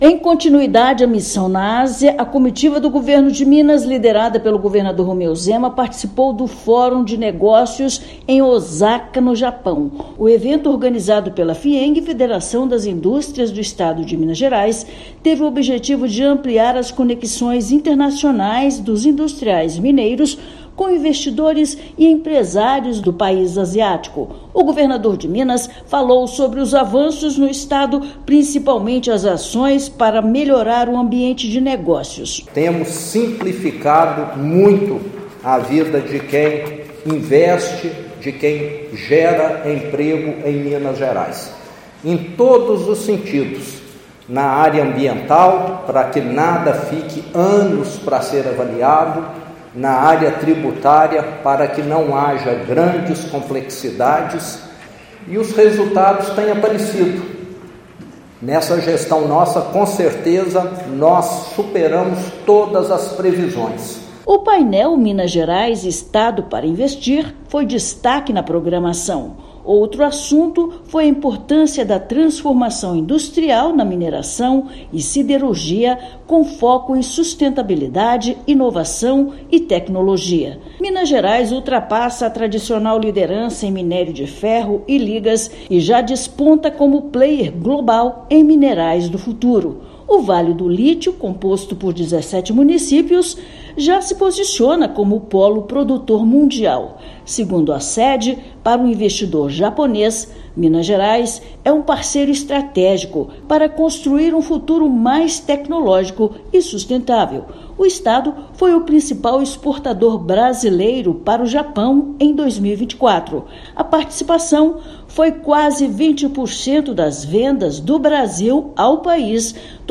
Brazil Japan Business Forum levou potencialidades do estado para firmar negócios no mercado asiático. Ouça matéria de rádio.